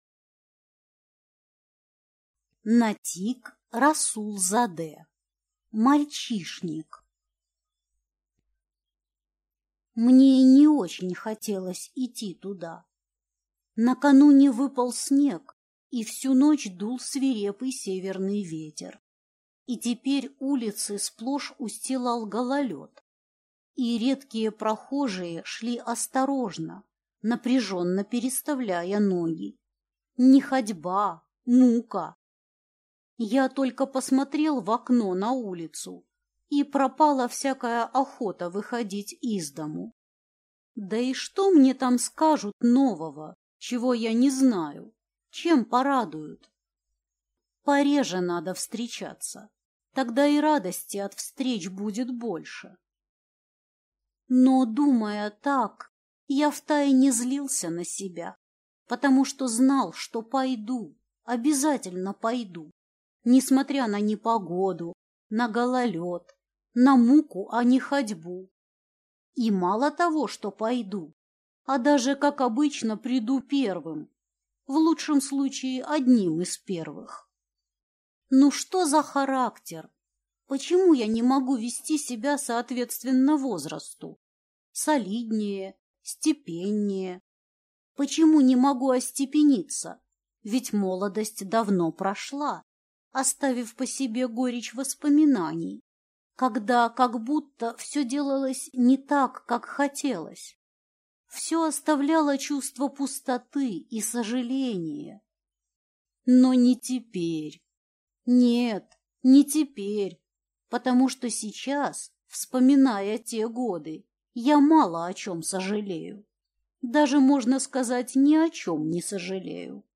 Аудиокнига Мальчишник | Библиотека аудиокниг